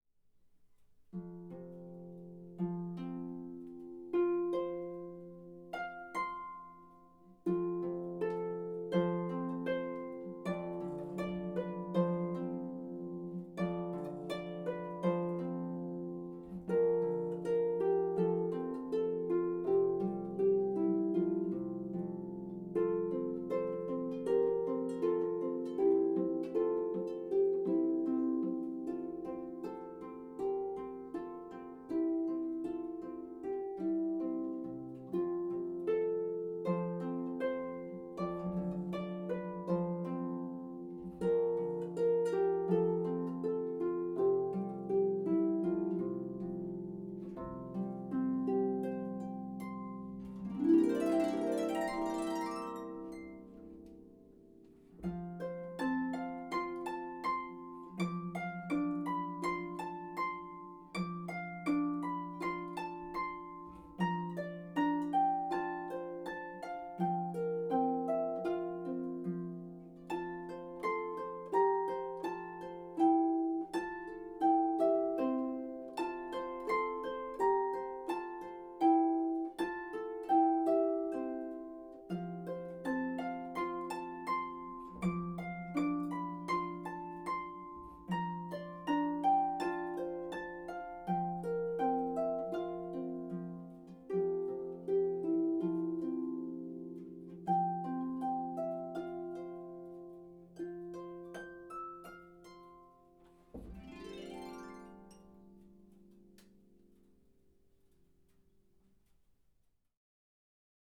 traditional folk song
solo pedal harp